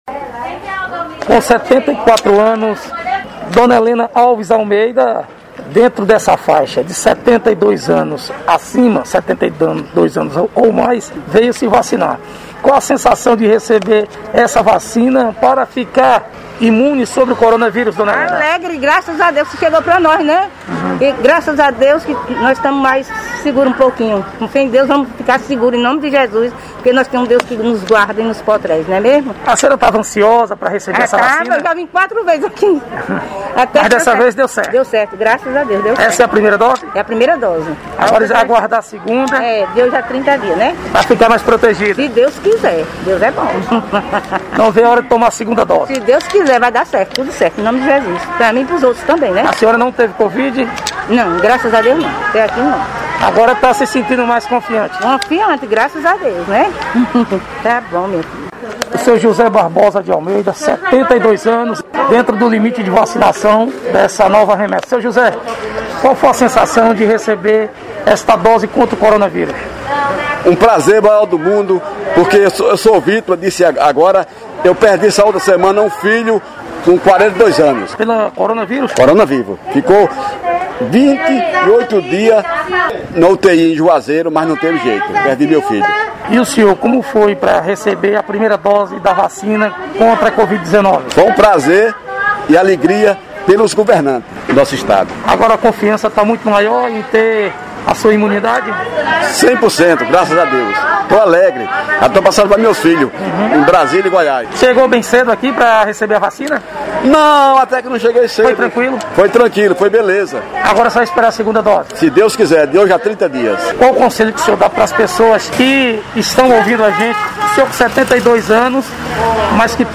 Reportagem: relatos de idosos